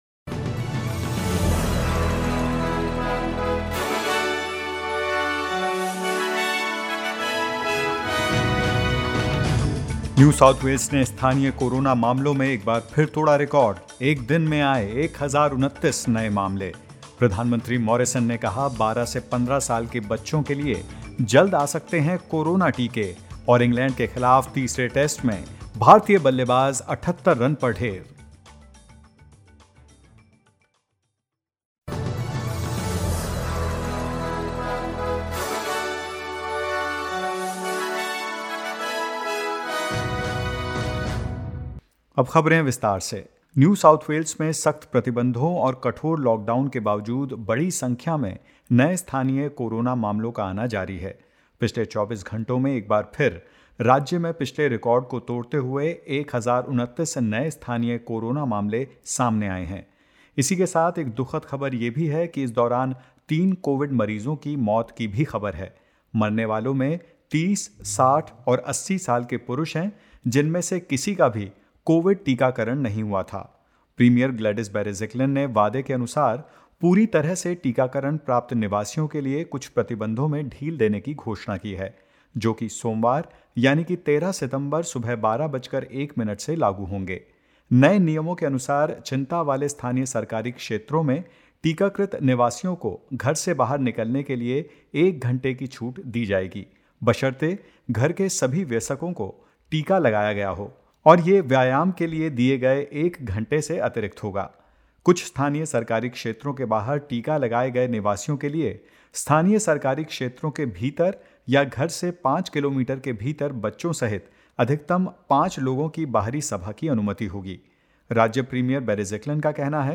In this latest SBS Hindi News bulletin of Australia and India: Lockdown in regional NSW extended until 10 September; Queensland will build a quarantine facility near Toowoomba and more.